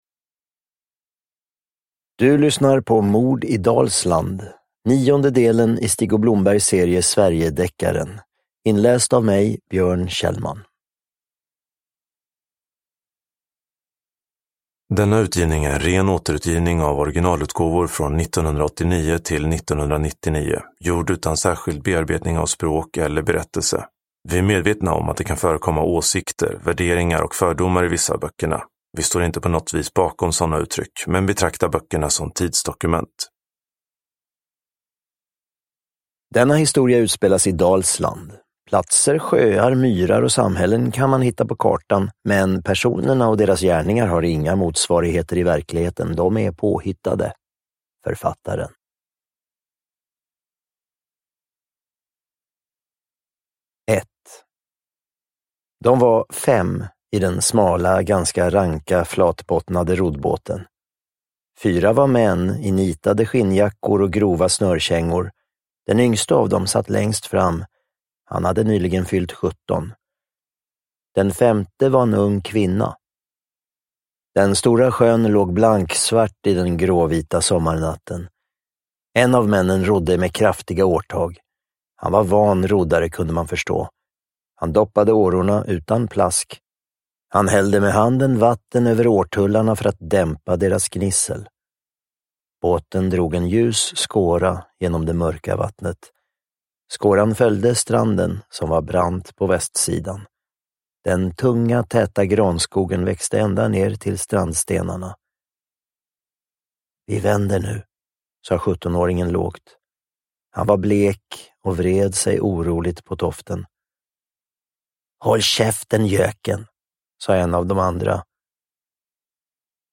Uppläsare: Björn Kjellman